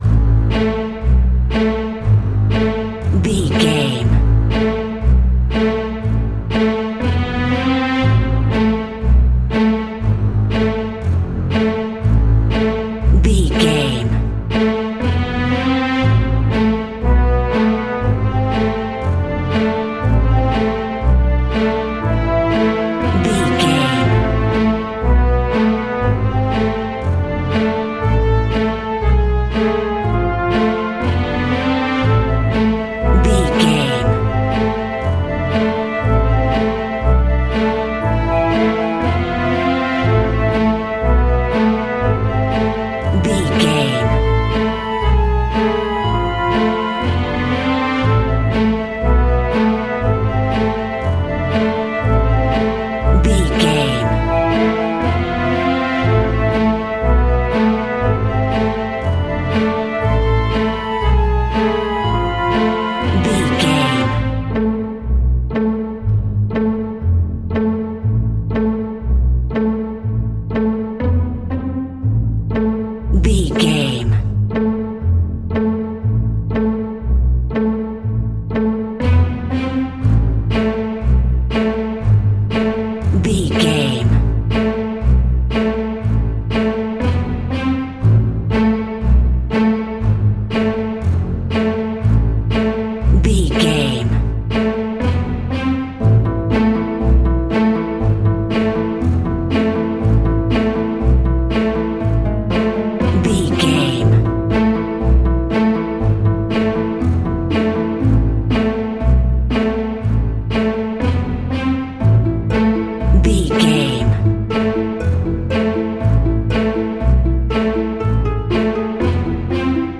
In-crescendo
Aeolian/Minor
scary
ominous
dark
suspense
eerie
strings
brass
flute